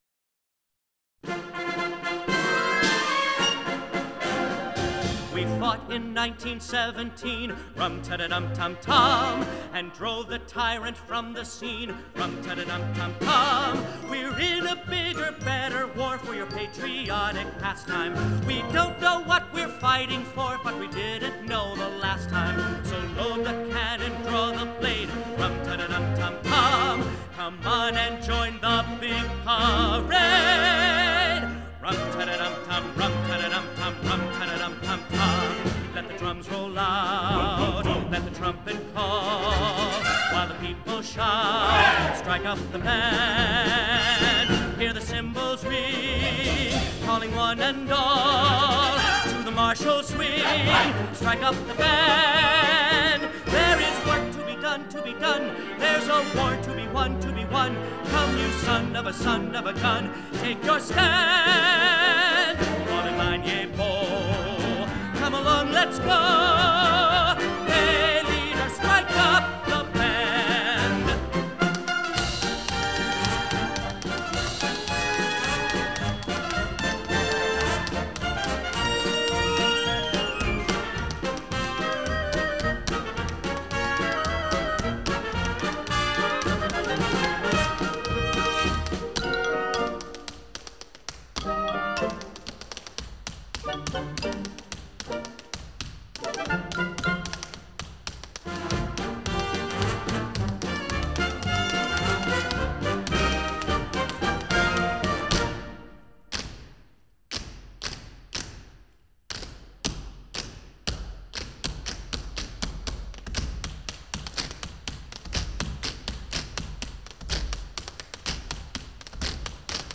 1990 Studio Cast
Genre: Musical